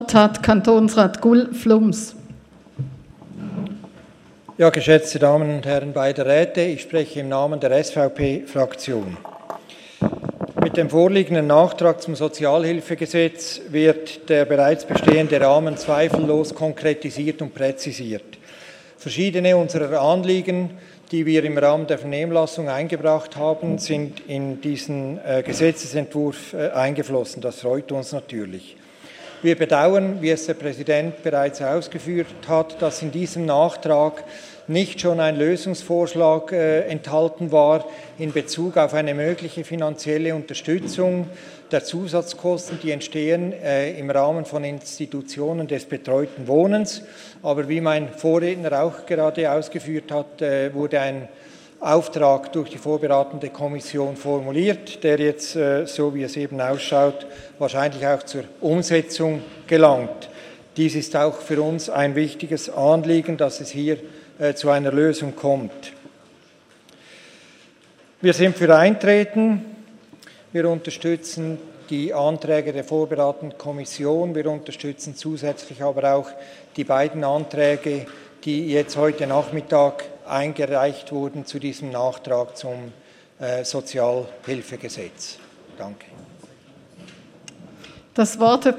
17.9.2018Wortmeldung
Session des Kantonsrates vom 17. bis 19. September 2018